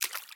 Footsteps_Water_5.ogg